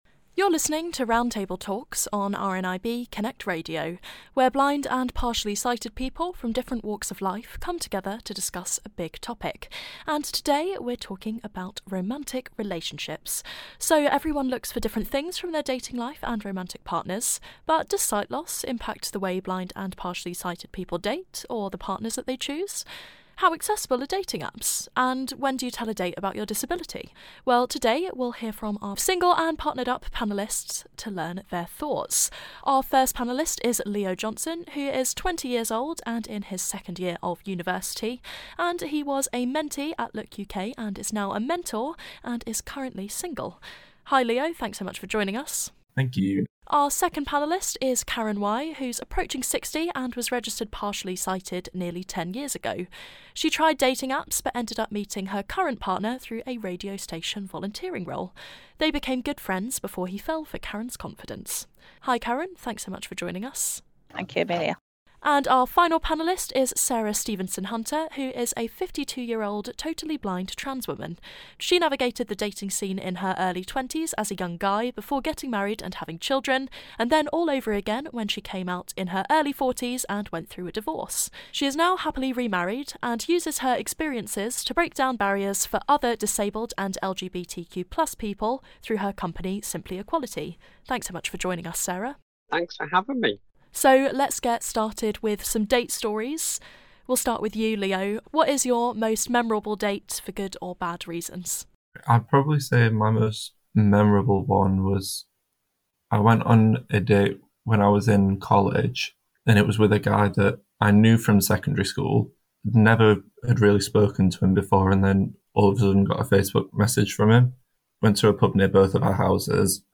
Romantic Relationships - Roundtable